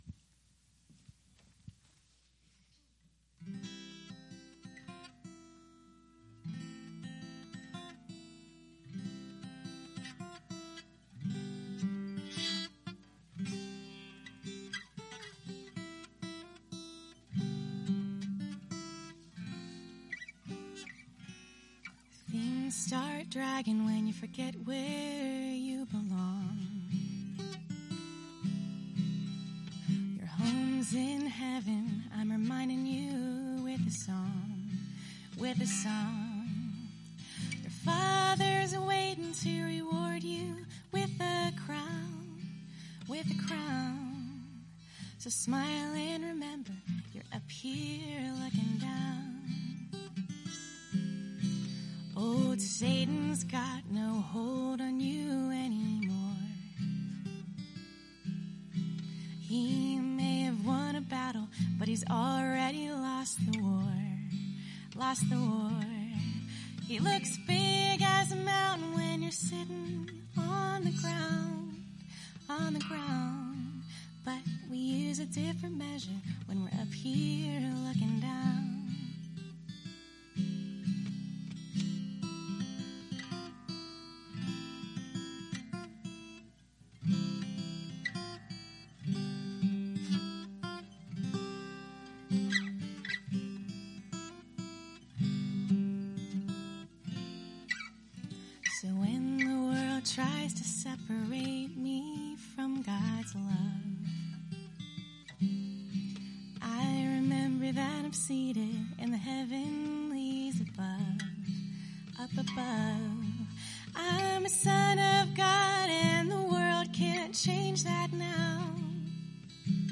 An expository, verse-by-verse, teaching of 1 Corinthians 15.